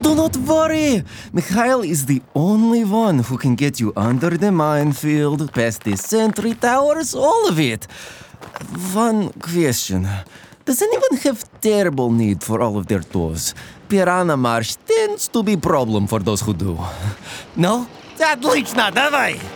russian | natural
Russian.mp3